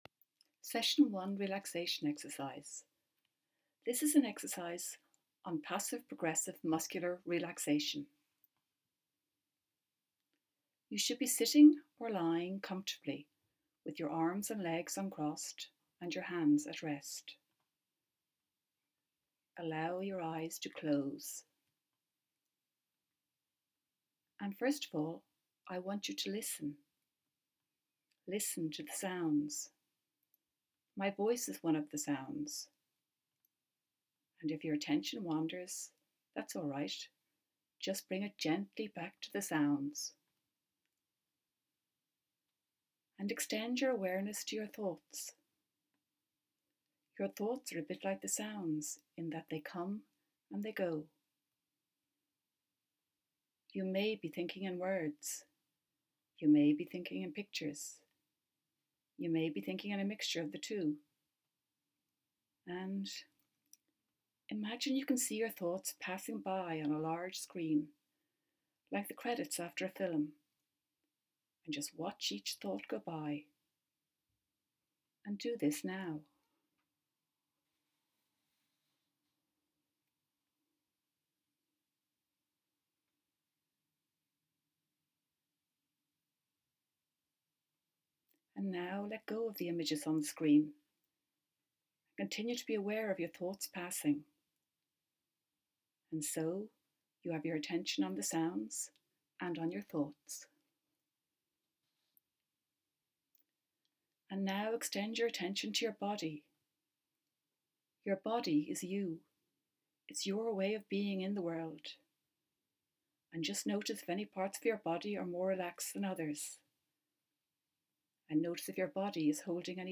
Relaxation Exercise – Passive Progressive Relaxation
Session-1-Relaxation-Exercise-V2.m4a